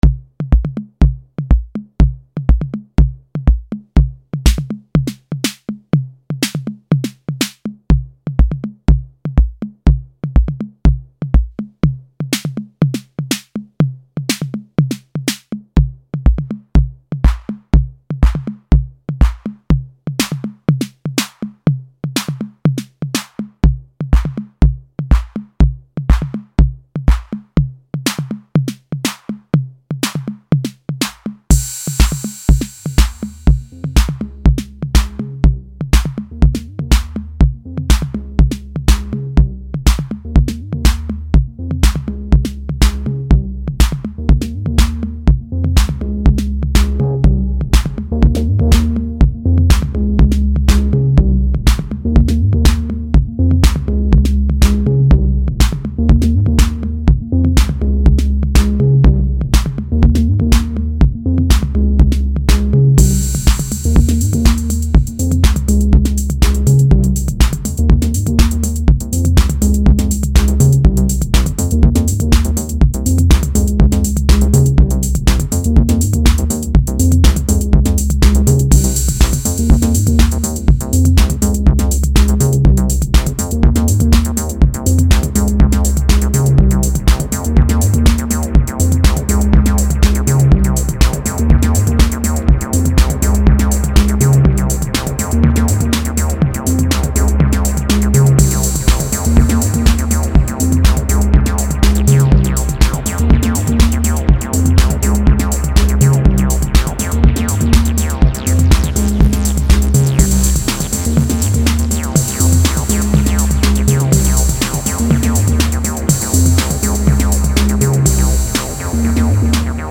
acid is fun in that you can just make things without really needing to put too much of your brain on it.
Roland TB-3 - Behringer TD-3 , RD-6
Music / House